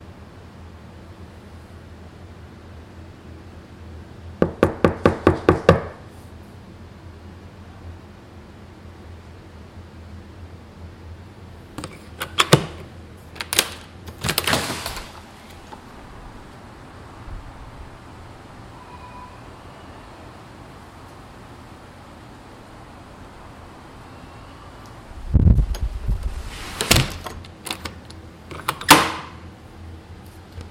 knocking at door
描述：a quick knock at the door. Recorded in home studio with a Zoom h2n microphone using Audacity sound software.
标签： knocks wood doorknock knocking door knock
声道立体声